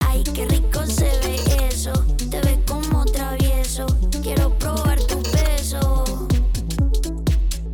▼RIMEオフのサウンド